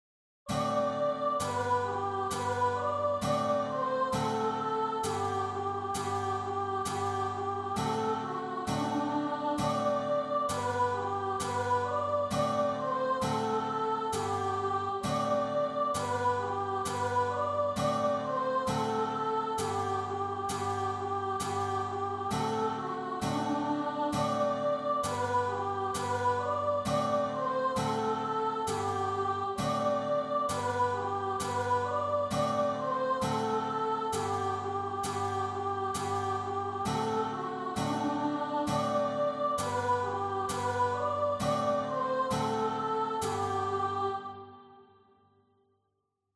Begleitakkorde für Gitarre / Klavier und Gesang